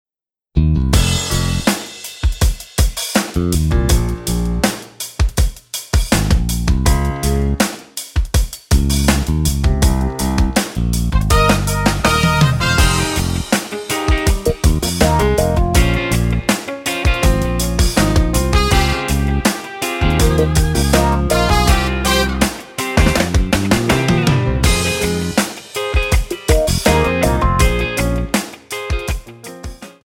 Wersja instrumentalna do piosenki